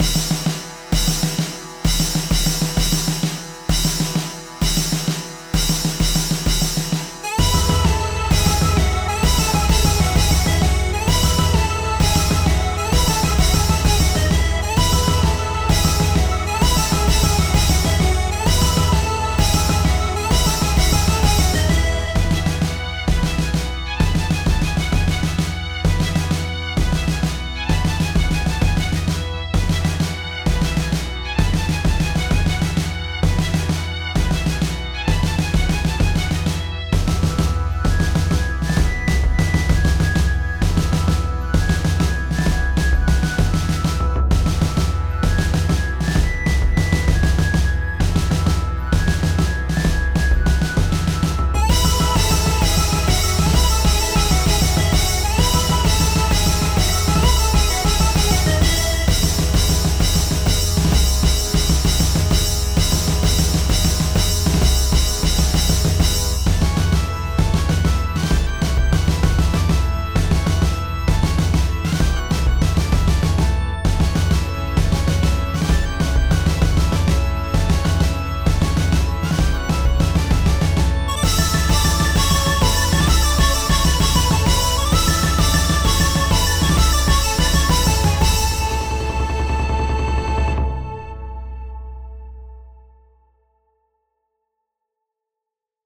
BPM51-65
Audio QualityPerfect (Low Quality)
TOY MARCH